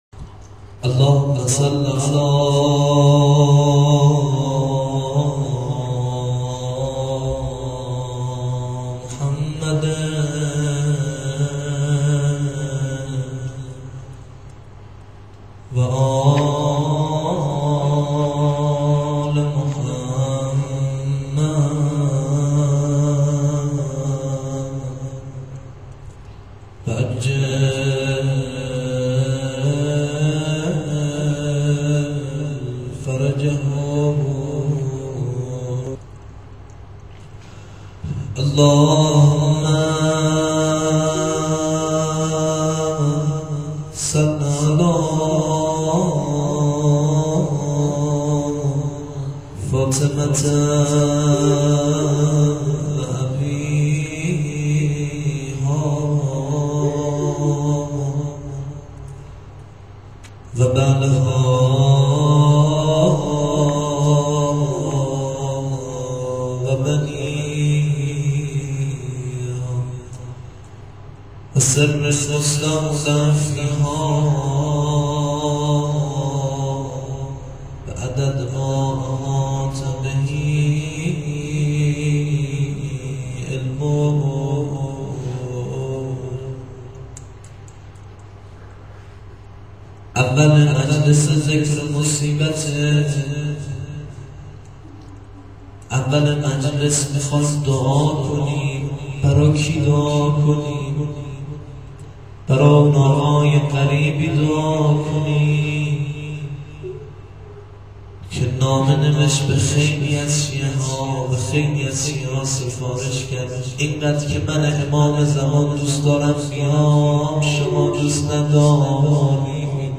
روضه-و-مناجات.wma